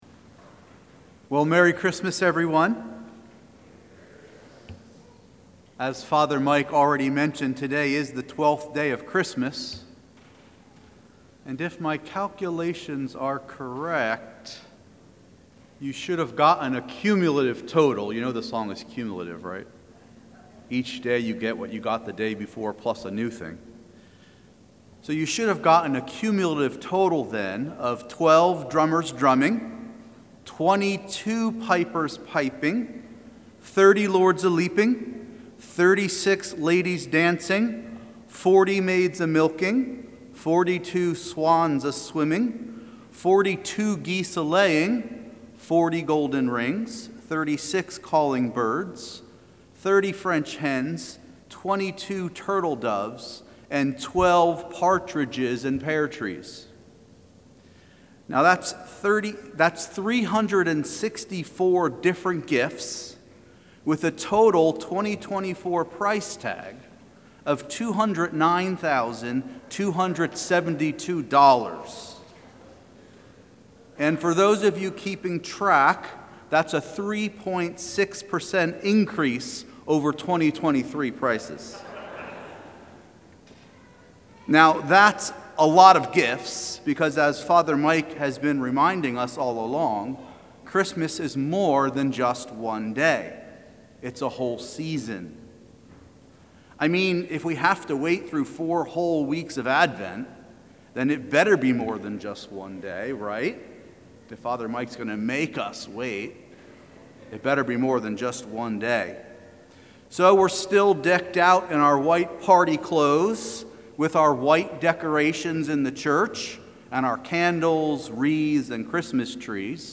Sermon Come, Let Us Adore Him